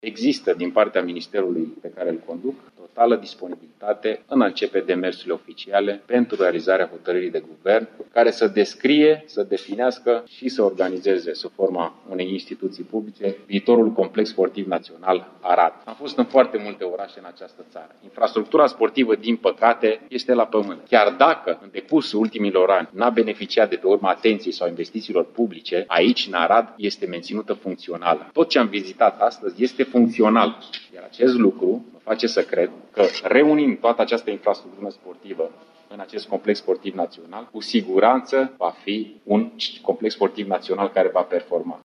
Prezent la Arad, ministrul Ionuț Stroe a promis că va face demersuri pentru adoptarea unei hotărâri de guvern prin care să fie reunită infrastructura sportivă din Arad, în condițiile în care, spre deosebire de alte orașe, administrația locală nu a lăsat în paragină patrimoniul: